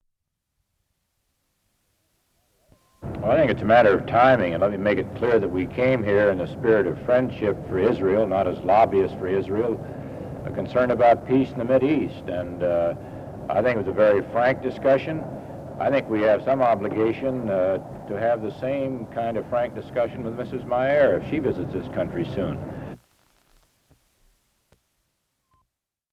Senator Bob Dole speaks briefly on the state of affairs in the Middle East and the United States' relationship with Israel.
radio programs